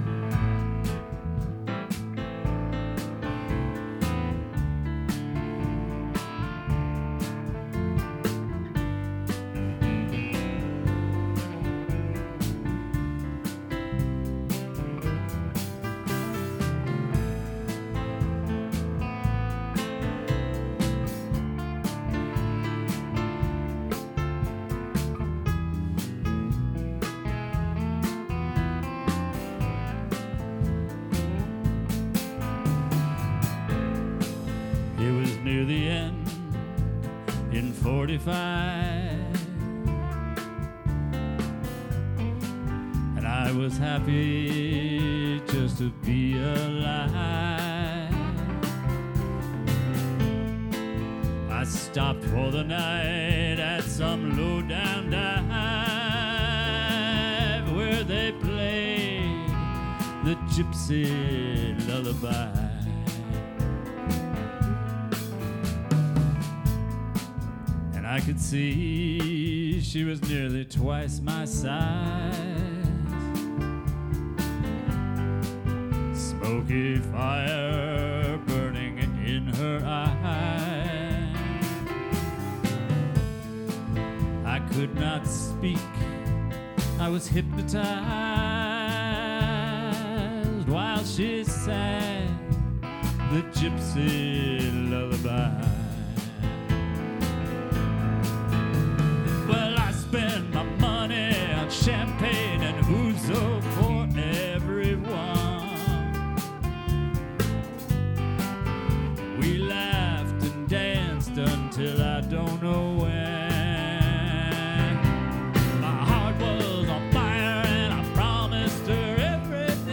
guitarist
bassist
drummer
Rehearsal